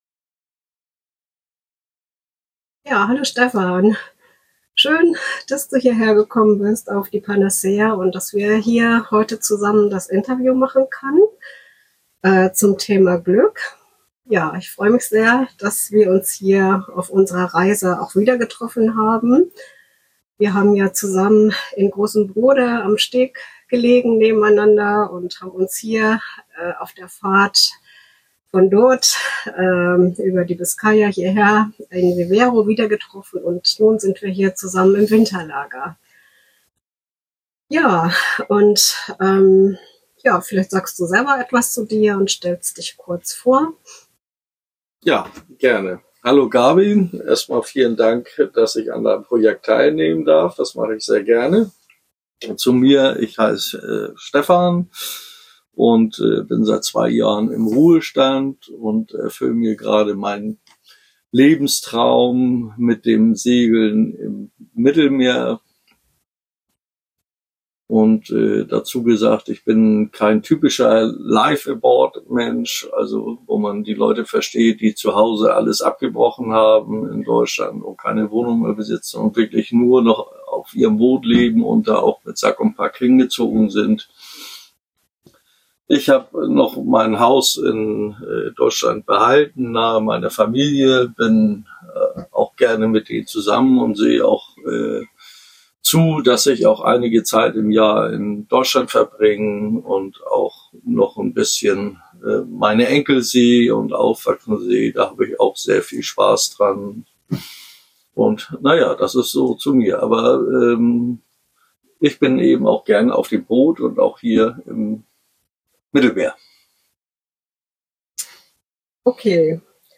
Dabei spielen Freiheit, Selbstbestimmung und der Kontakt zu Familie und Freunden eine zentrale Rolle. Ein inspirierendes Gespräch über die Kunst, das Gute im Leben zu sehen, Herausforderungen mit Pragmatismus zu begegnen und die eigenen Prioritäten bewusst zu setzen.